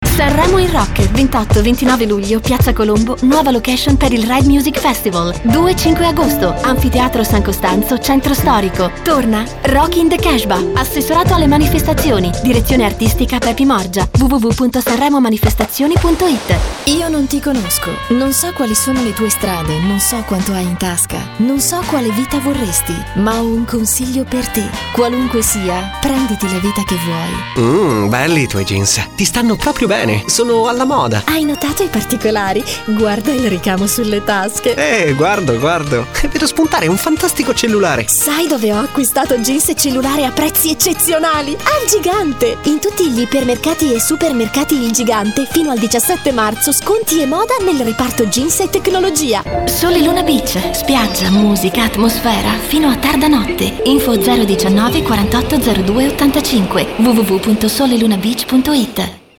Professional home recording studio. My voice is friendly young and smiling. Smooth and professional for presentations. Warm for narrations.
Sprechprobe: eLearning (Muttersprache):
Italian professional female Voiceover Talent.